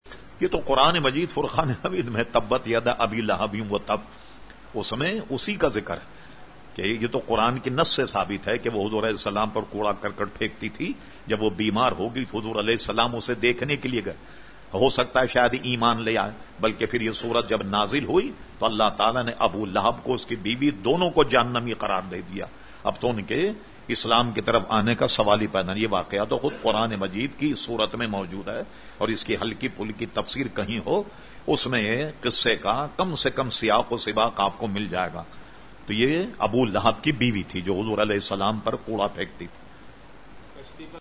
Q/A Program held on Sunday 4 March 2012 at Masjid Habib Karachi.